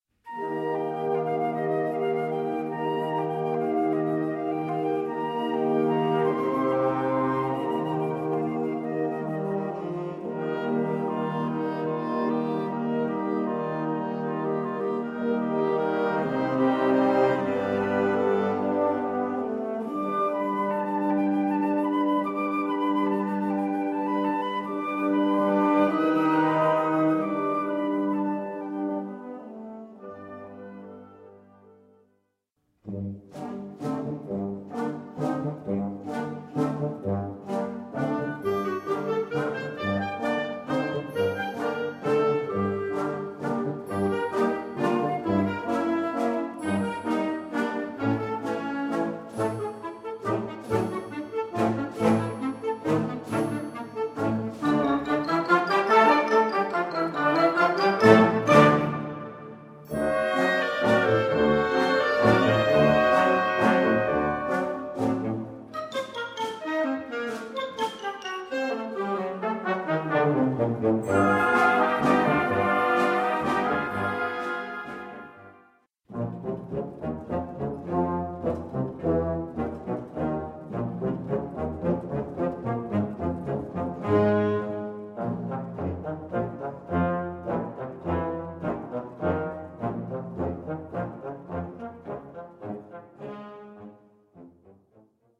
Gattung: Suite
Besetzung: Blasorchester
für Jugendblasorchester.